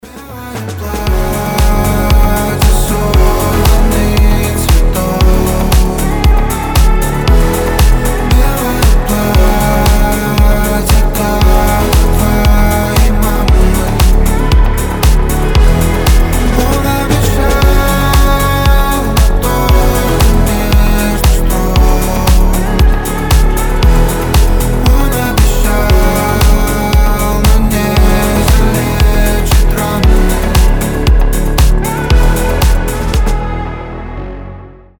• Качество: 320, Stereo
красивый мужской голос
Synth Pop